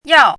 chinese-voice - 汉字语音库
yao4.mp3